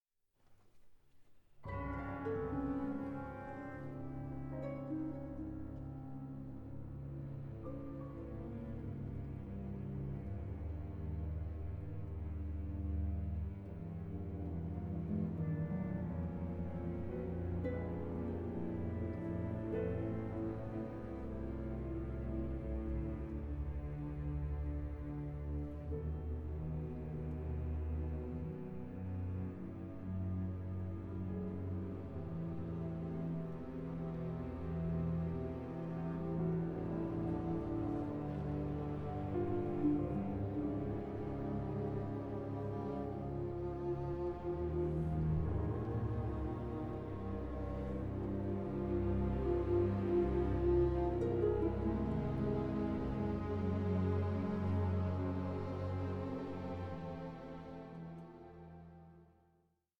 for violin and chamber orchestra
CONTEMPORARY SOUND WORLD ROOTED IN TRADITION